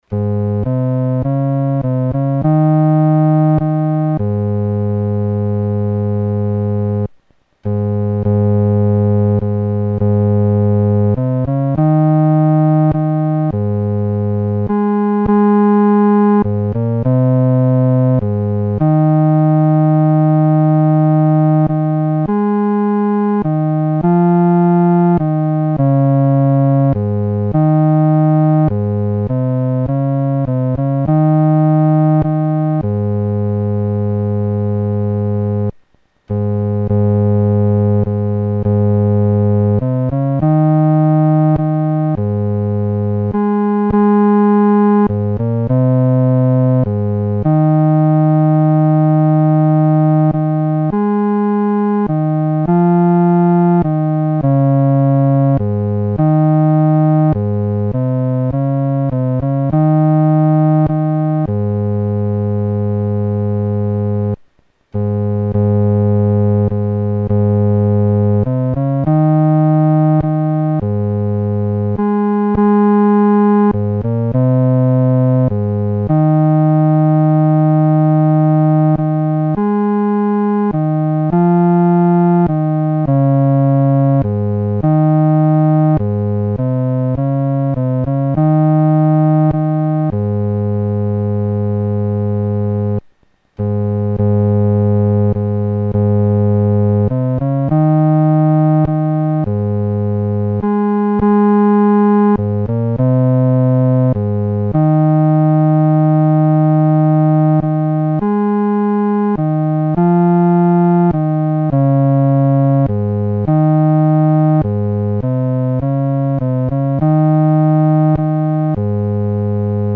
男低伴奏